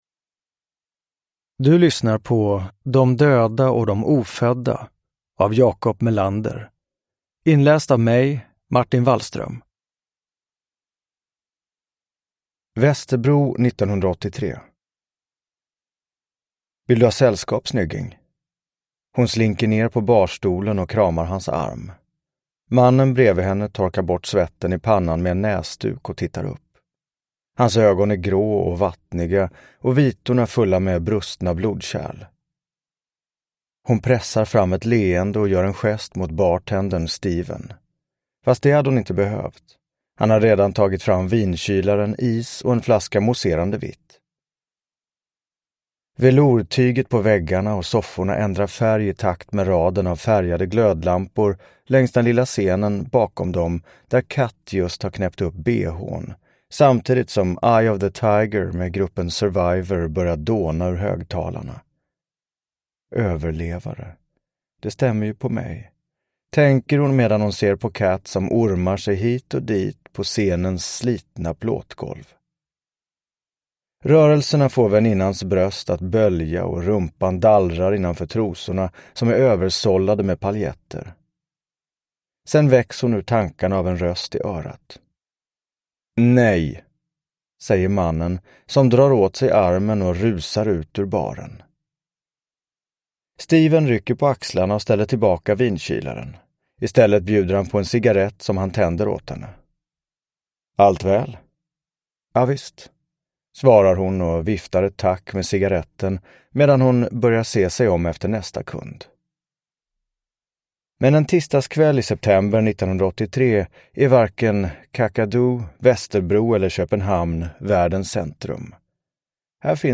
De döda och de ofödda – Ljudbok
Uppläsare: Martin Wallström